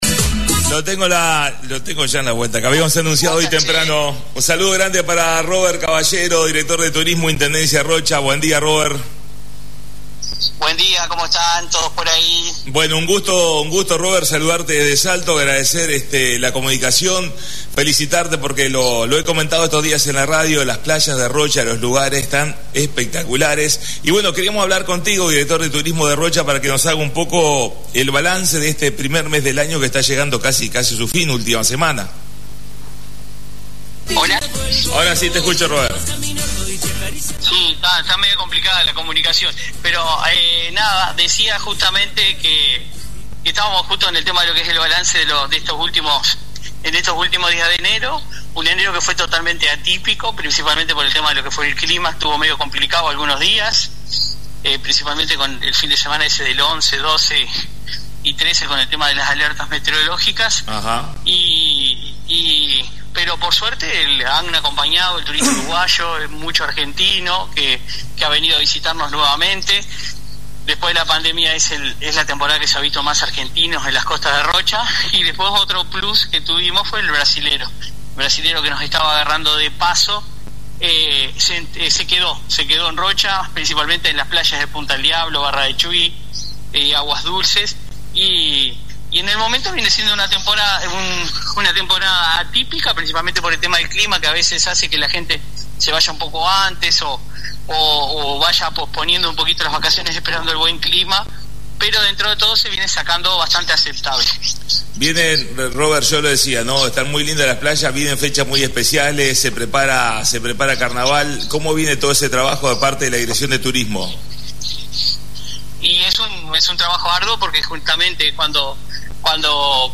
Comunicación en vivo con Robert Caballero Director de Turismo Intendencia de Rocha